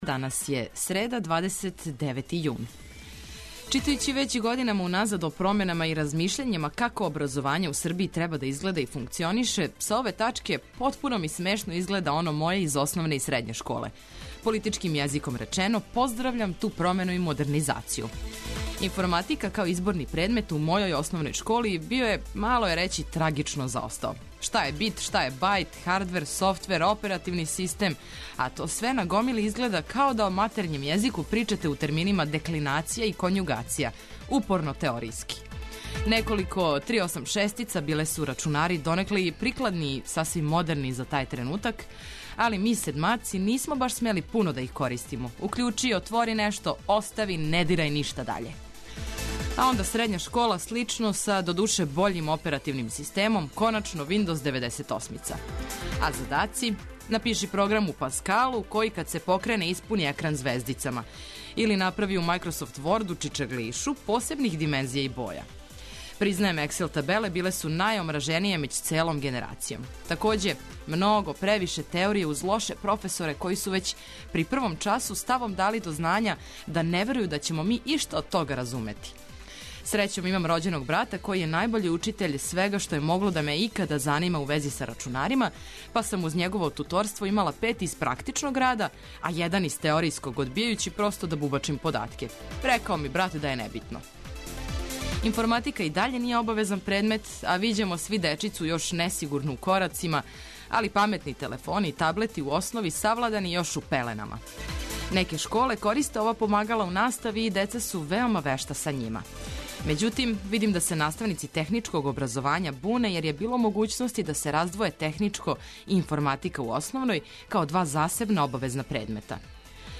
Разбуђивање сваког дана током целе године је од 6 изјутра уз Двестадвојку, обиље добре музике, информација и аргумената зашто да се осмехнете!